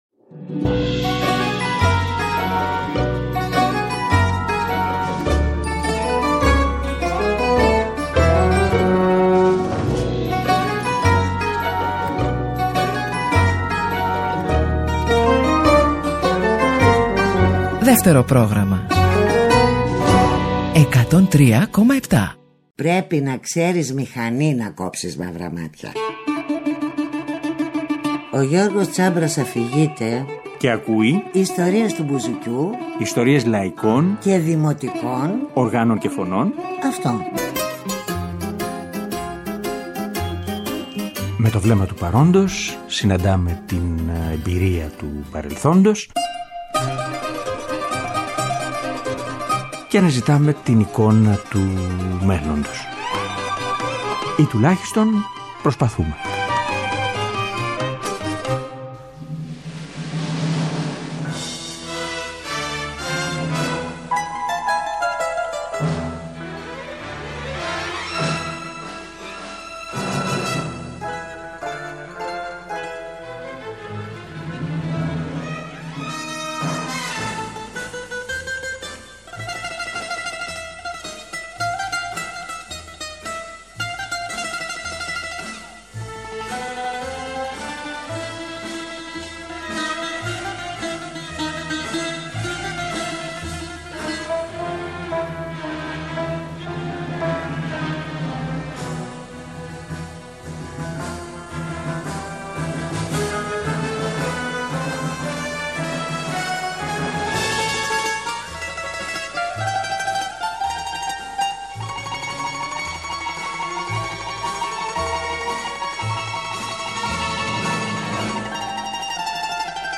μπουζούκι